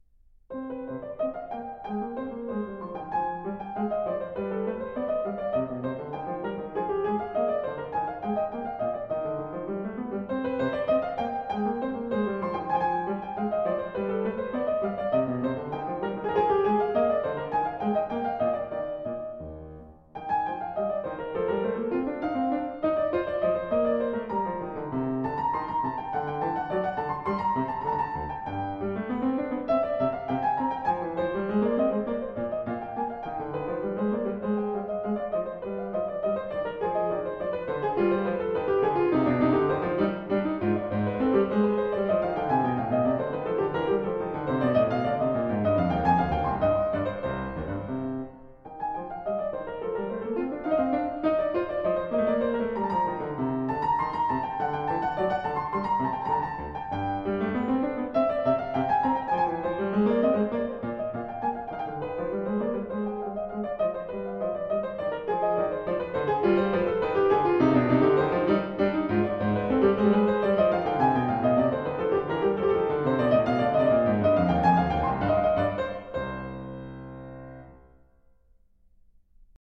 Air
Air ~1570 (Baroque) 40 works by 19 composers Keyboard [40%] Vocal [40%] ...